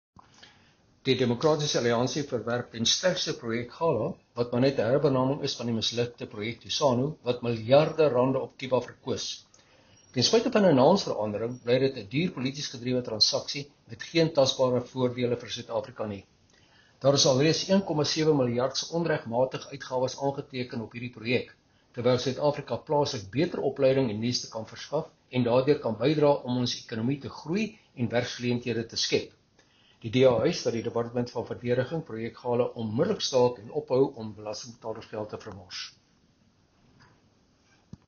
Afrikaans soundbites by Chris Hattingh MP.